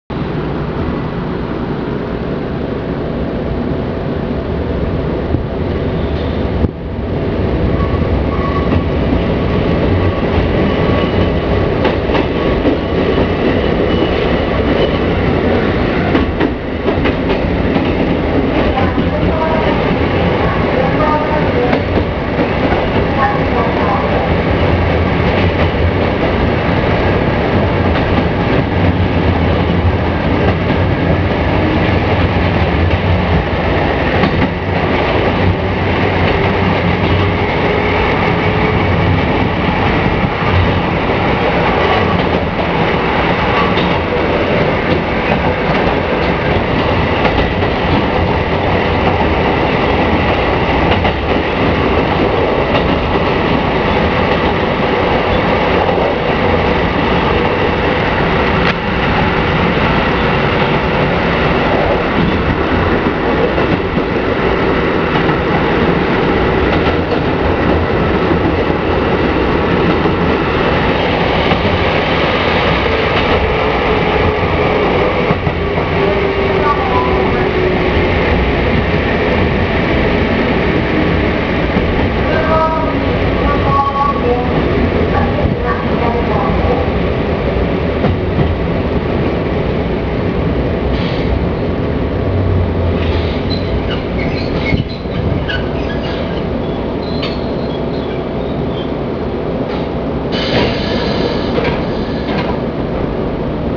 〜車両の音〜
・1200形走行音
【長尾線】瓦町→片原町（1分42秒：552KB）…1255にて収録
４つドアになって加速度が若干下げられた以外は基本的に京急1000形と同一で製造された車両なので、モーター音に差は有りません。